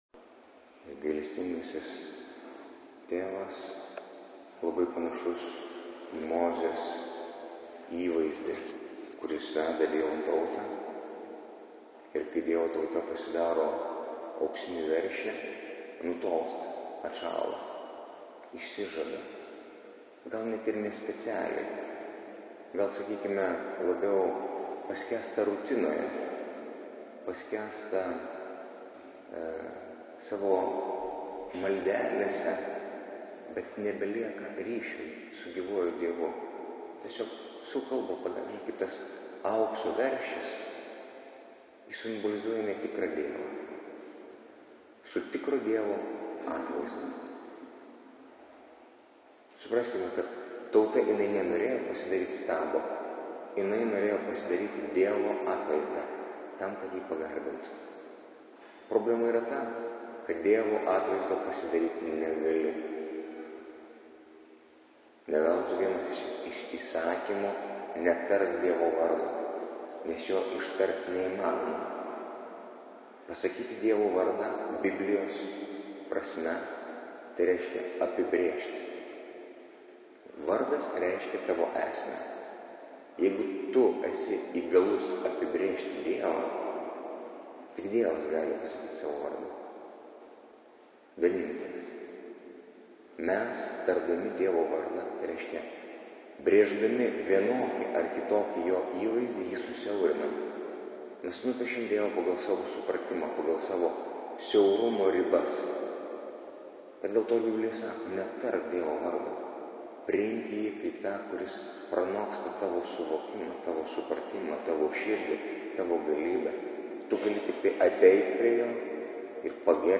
Audio pamokslas Nr1: 2016-09-11-xxiv-eilinis-sekmadienis